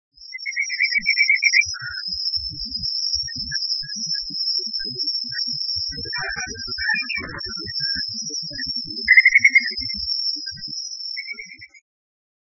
2125d「鳥の鳴声」
〔チュウシャクシギ〕ホイピピピピピピ／干潟や水田などで見られる，普通・通過，42